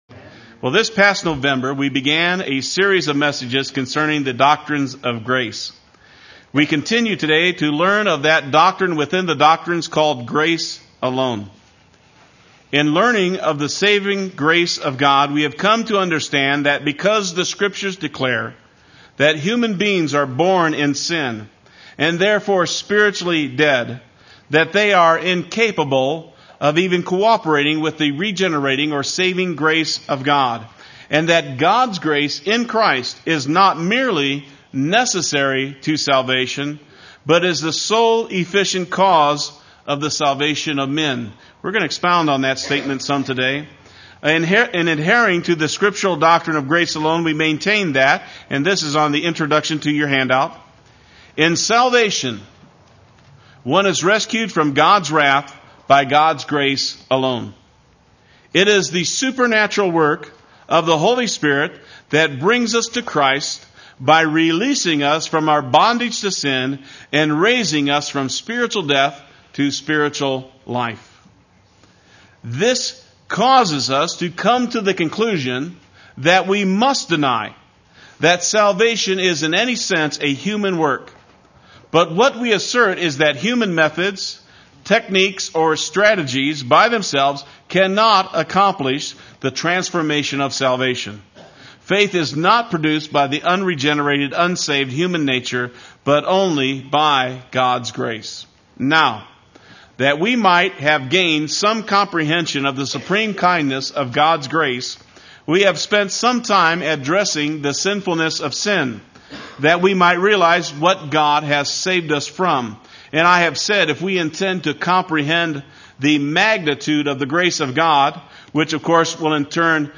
Play Sermon Get HCF Teaching Automatically.
Grace Alone Part III Sunday Worship